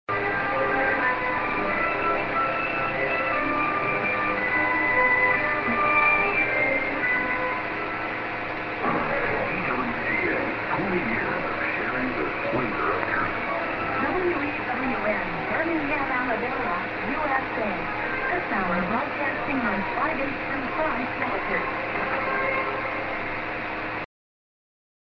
music->ID(man+women)->prog